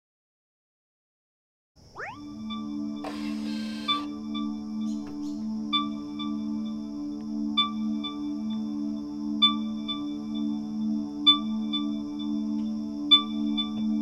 You can hear the electric parking brake release, inverter hum and a safety “pinging” noise to alert pedestrians.